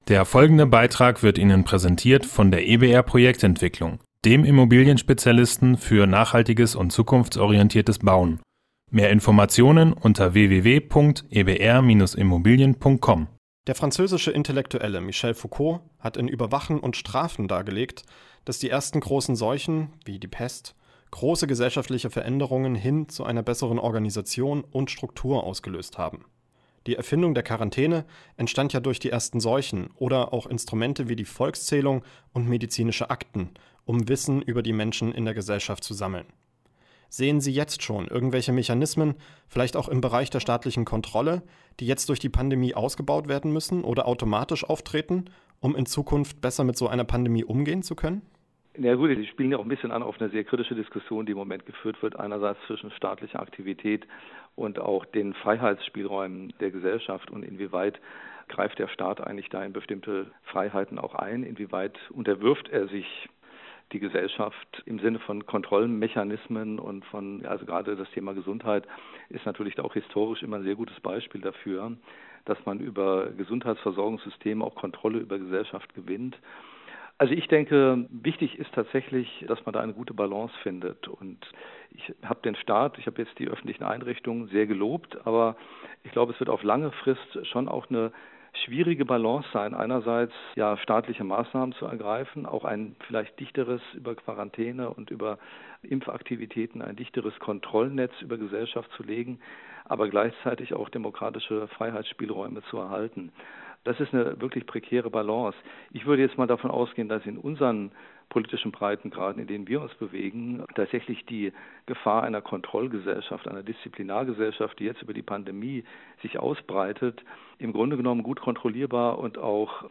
Sie hören jetzt den dritten und letzten Teil des Interviews.